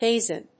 /ˈhezʌn(米国英語), ˈheɪzʌn(英国英語)/